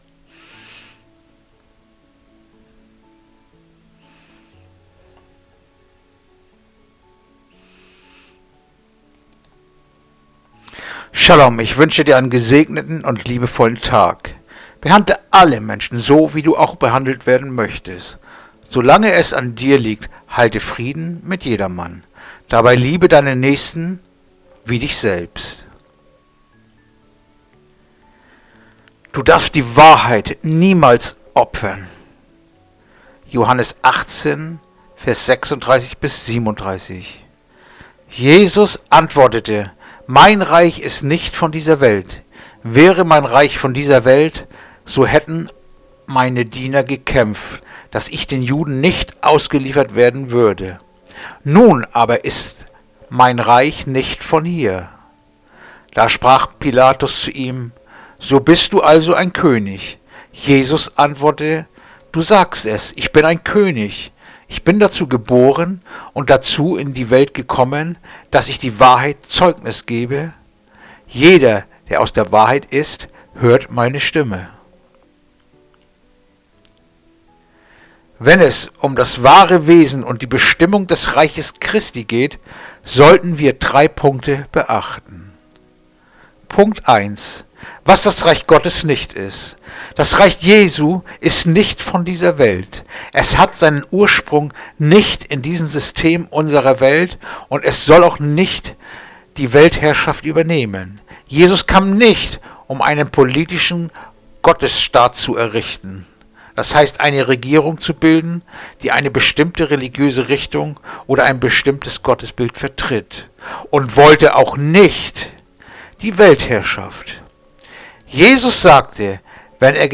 Andacht-vom-05.-Mail-Johannes-18-36-37